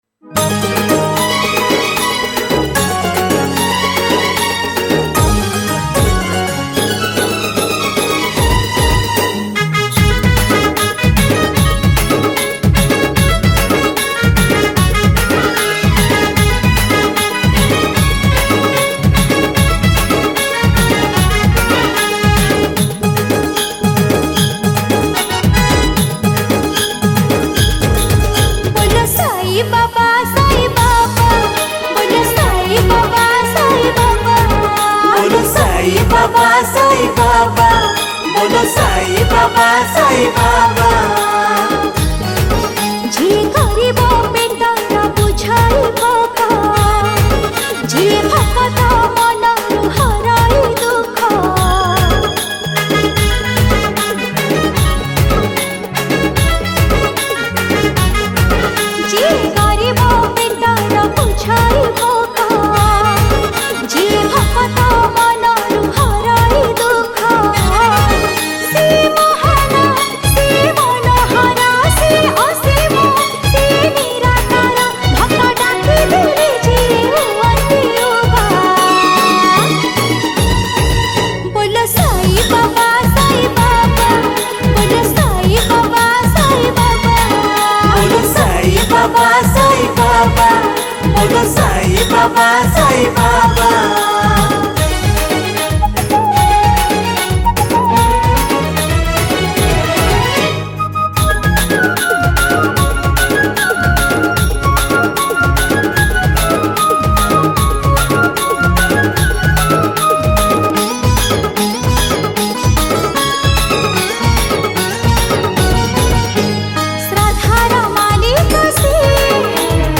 Sai Baba Bhajan 2021 Songs Download